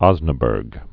(ŏznə-bûrg)